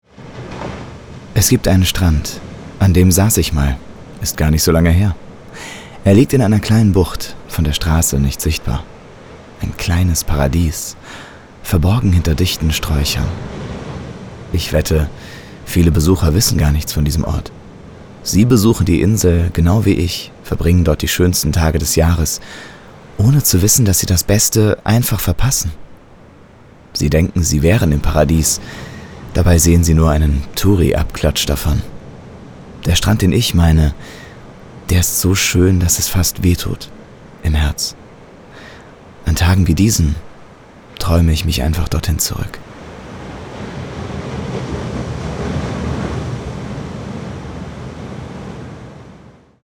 sehr variabel, markant, hell, fein, zart, plakativ
Mittel minus (25-45)
Eigene Sprecherkabine